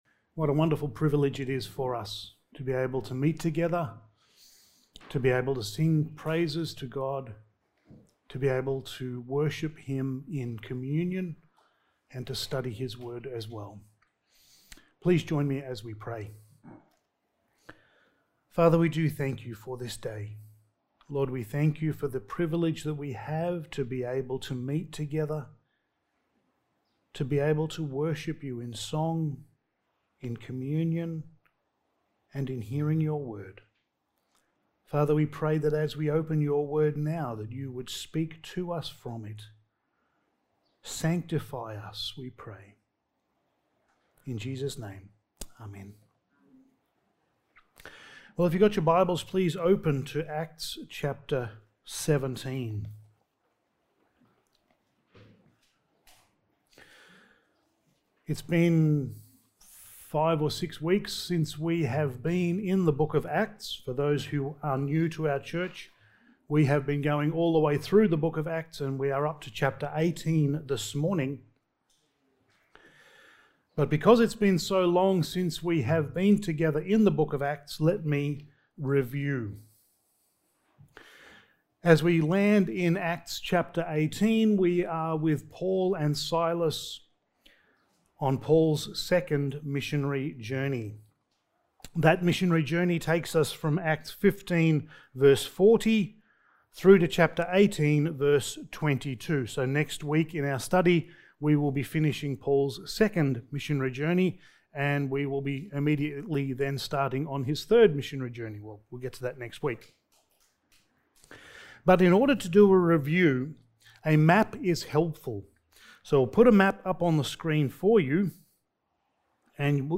Passage: Acts 18:1-17 Service Type: Sunday Morning